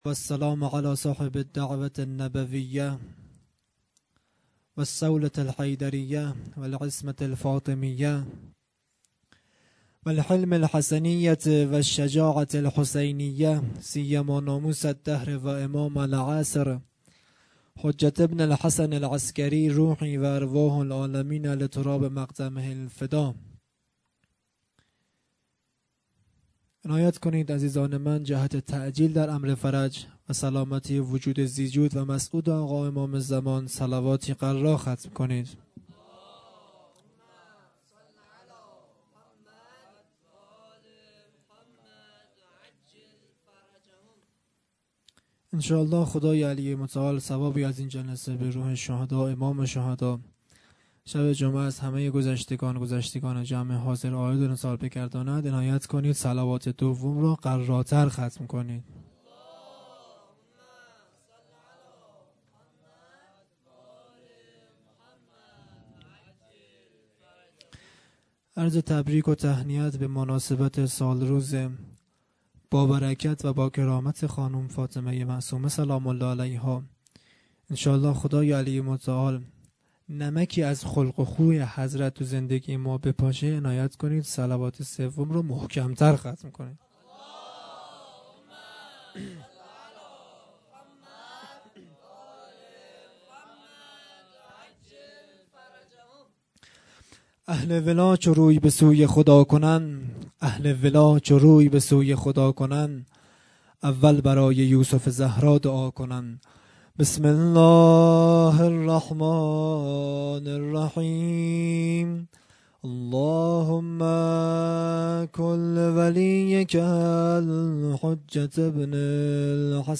خیمه گاه - هیئت بچه های فاطمه (س) - سخنرانی | دعا
جلسۀ هفتگی (به مناسبت ولادت حضرت معصومه(س))